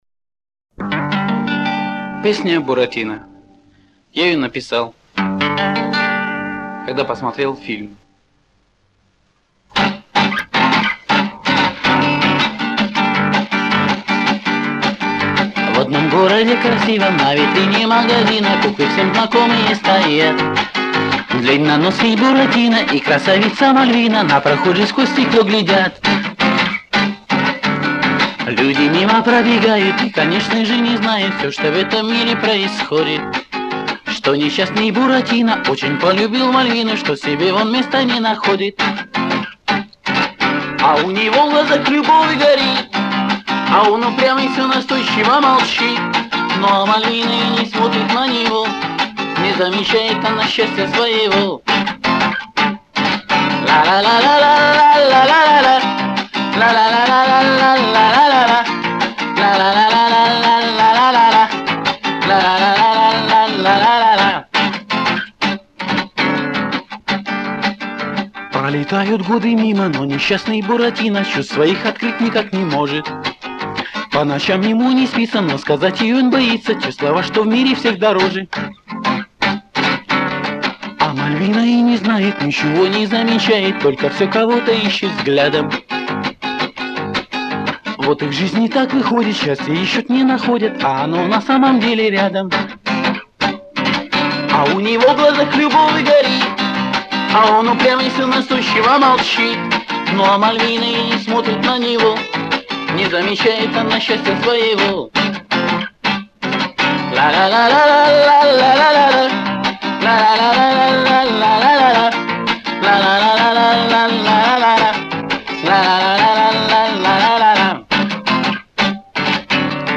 В основном, записи тех лет под гитару,проходят как неизвестные исполнители